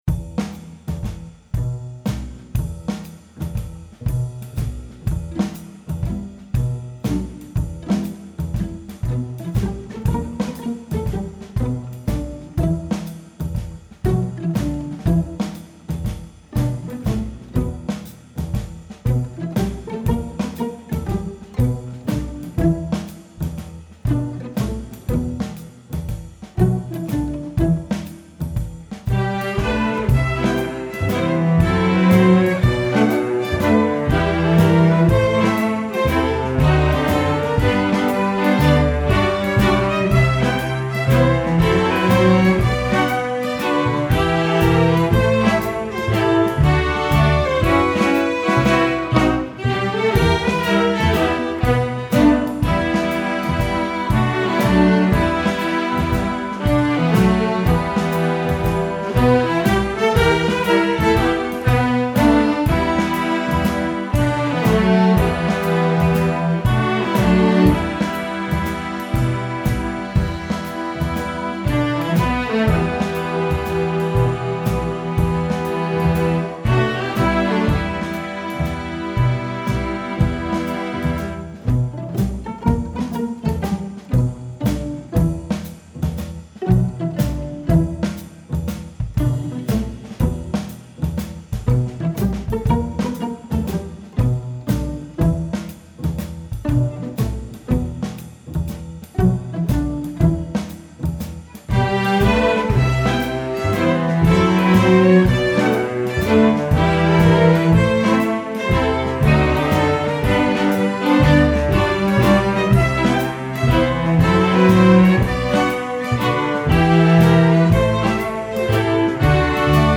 Instrumentation: string orchestra
jazz